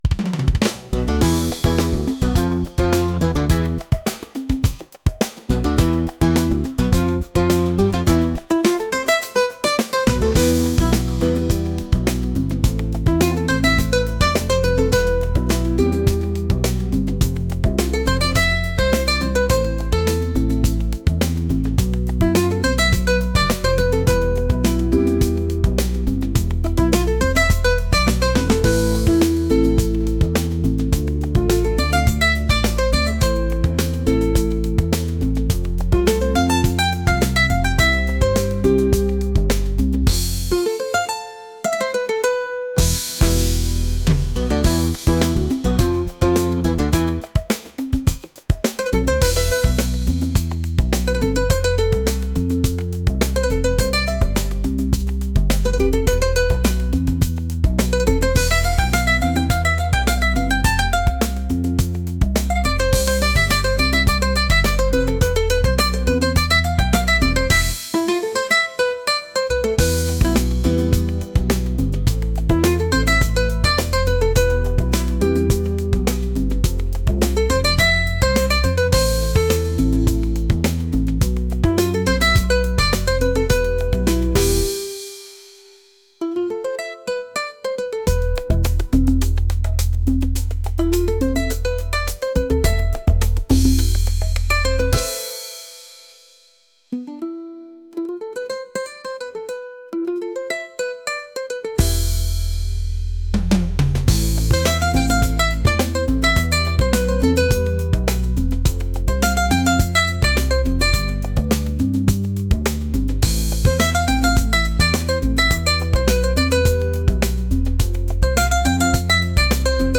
energetic | fusion | world